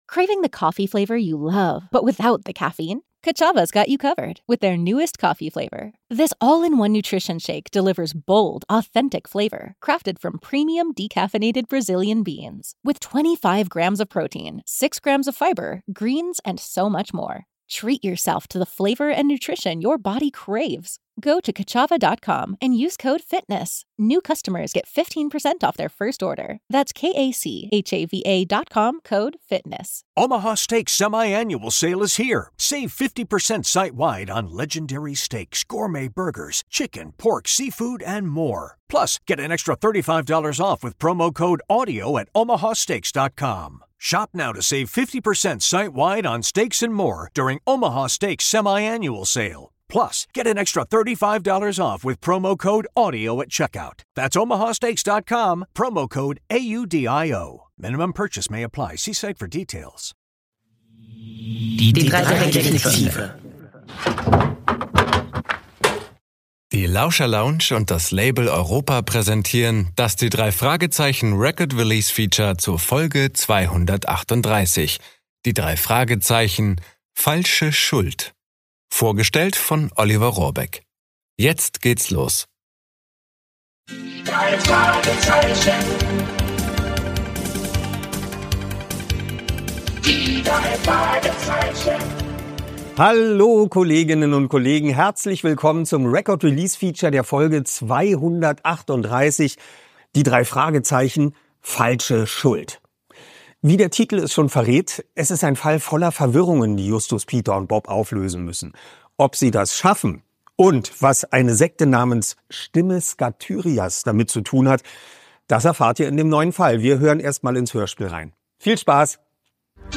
Sprecher: Oliver Rohrbeck